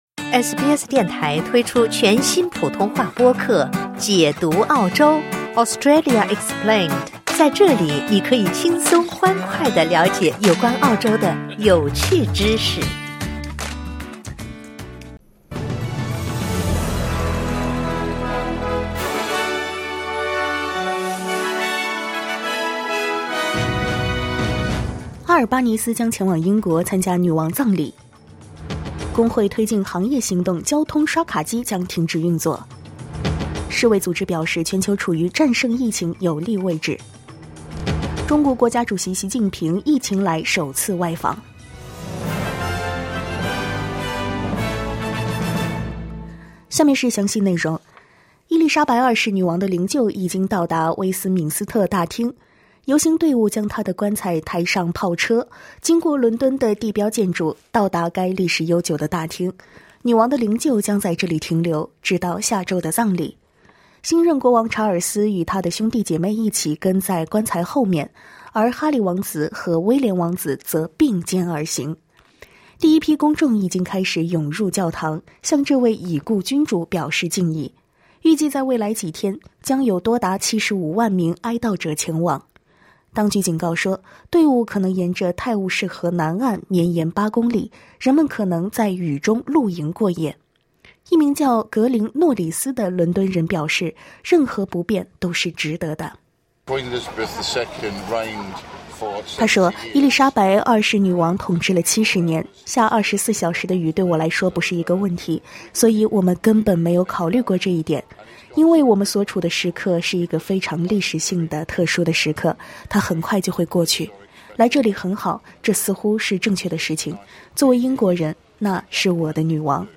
SBS早新闻（9月15日）